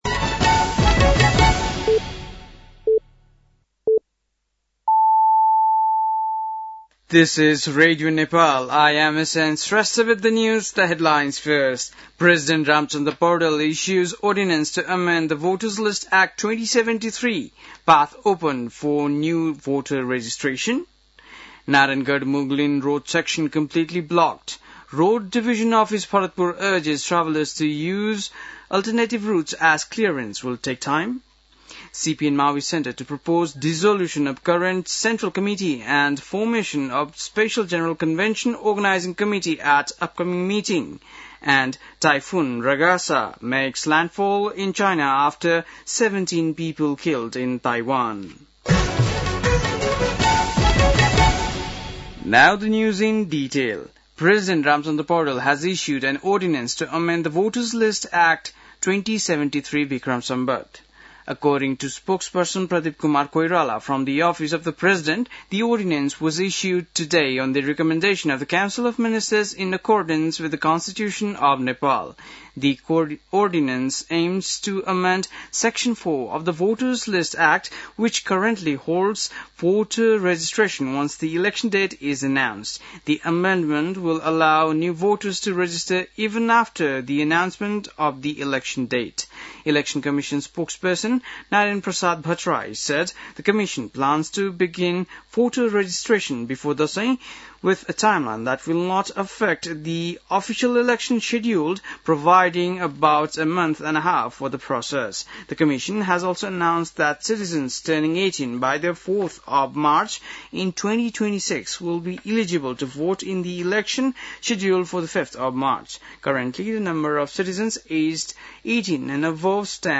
बेलुकी ८ बजेको अङ्ग्रेजी समाचार : ८ असोज , २०८२
8-PM-english-news-06-08.mp3